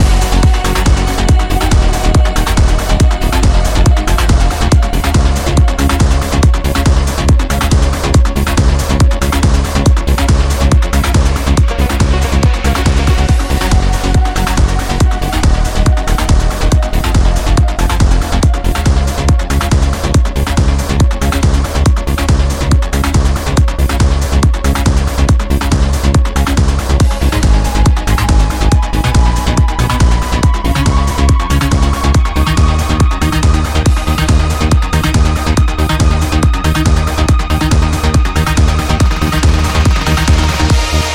Нажмите для раскрытия... а его нет... неее, мид-бас есть конечно, но низких частот очень мало, прям дифицит, бочка вообще не втему, она играет на средних частотах, на низах ее нет, средние частоты завышены, баланс инструментов на тройочку, куча информации в противофазе, в конце Асид вообще из другой оперы, на счет гармонии: я так понимаю это с # - мажор, значит во-первых в транс музыке 90% в миноре написано, во-вторых гармония в треке 4 ступень - 2 - 3 - 1 - квадрат 1 ступени, затем 5 - 6 - 1 - 2 - квадрат на 2 ступени (незнаю ) - в каком из учебников описана такая гармония? это не гармония